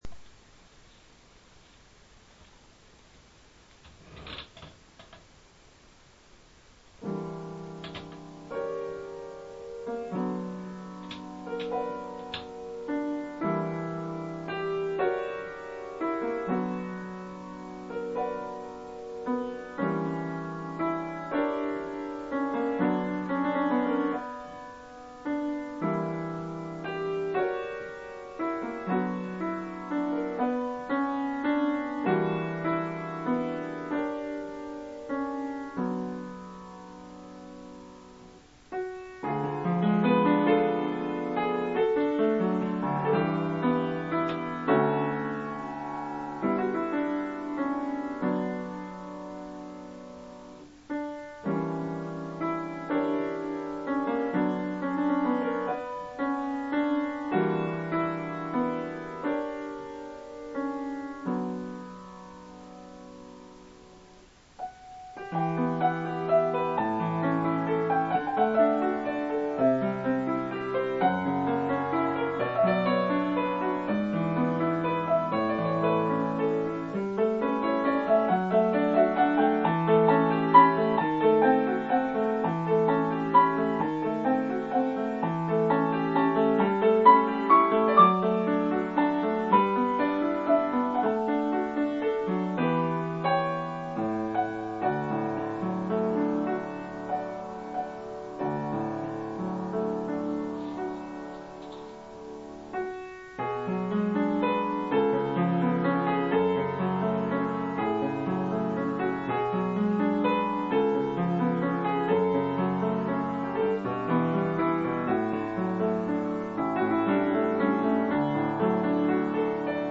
やっと録音した今年の曲…鈍らないうちに。
この曲では"嵐の予感"を、不安を煽るような低音の動きの上で。